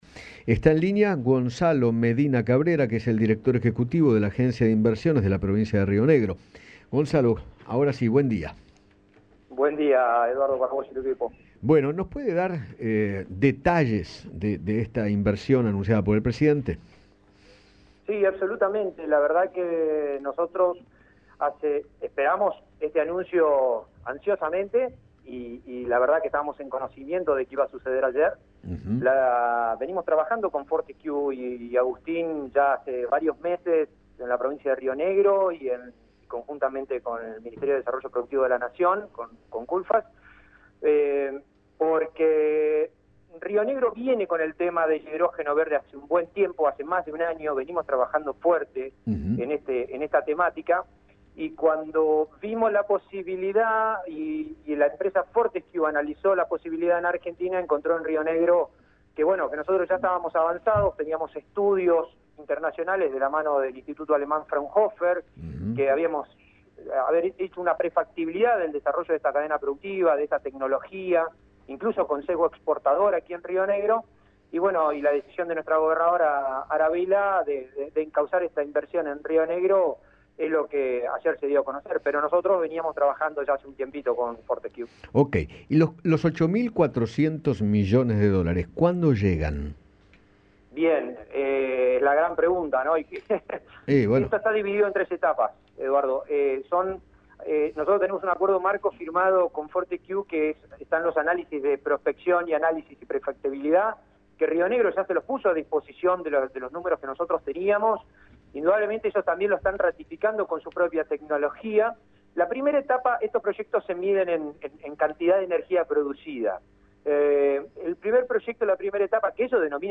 Gonzalo Medina Cabrera, director de la Agencia Río Negro Invierte, conversó con Eduardo Feinmann sobre la inversión que realizará la empresa energética australiana, Fortescue Future Industries, para producir hidrógeno verde y detalló cuántos puestos laborales serían creados.